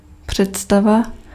Ääntäminen
US : IPA : [ˈnoʊ.ʃən]